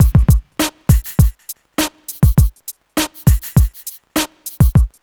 HF101BEAT2-R.wav